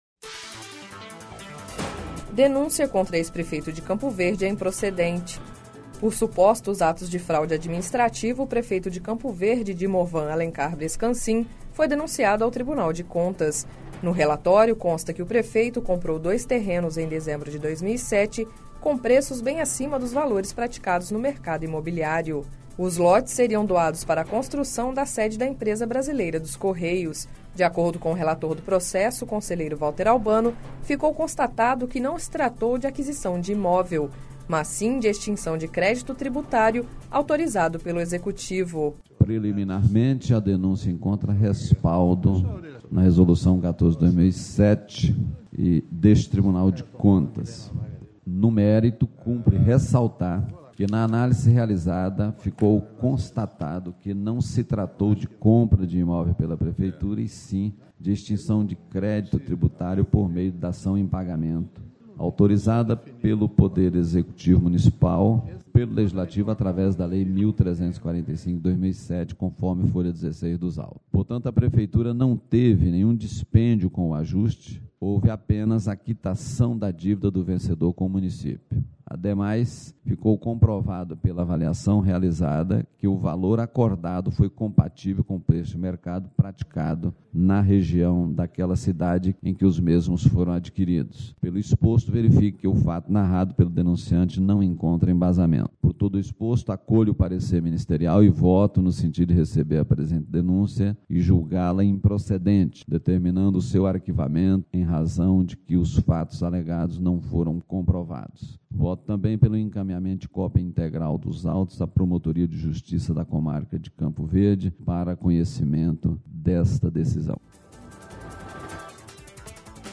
Sonora: Valter Albano – conselheiro TCE